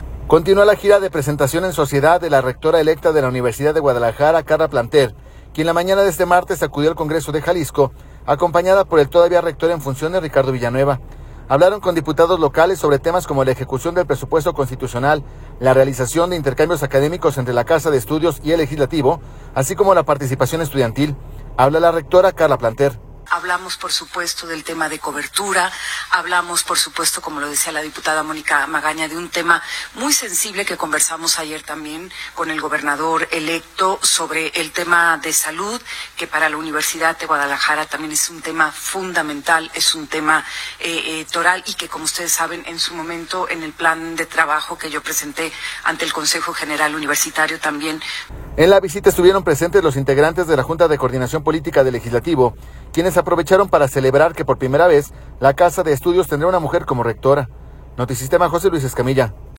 Hablaron con diputados locales sobre temas como la ejecución del presupuesto constitucional, la realización de intercambios académicos entre la casa de estudios y el legislativo, así como la participación estudiantil. Habla la rectora electa Karla Planter.